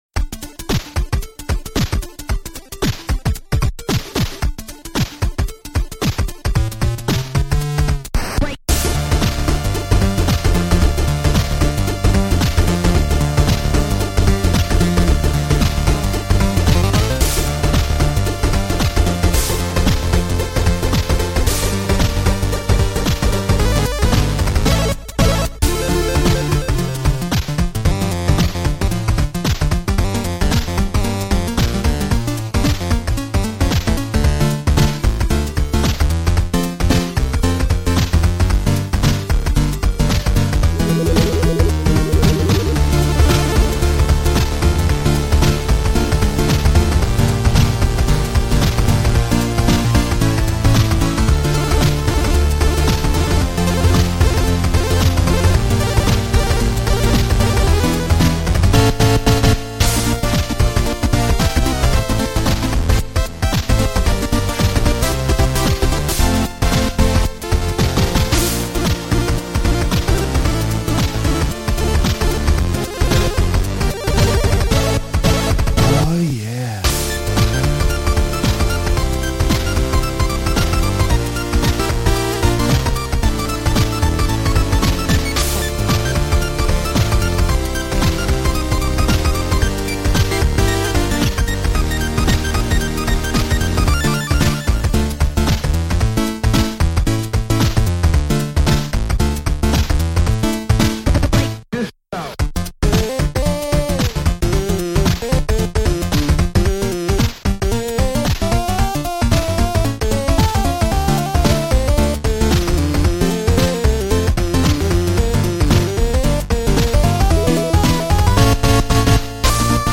I think this is the best chiptune I've made yet.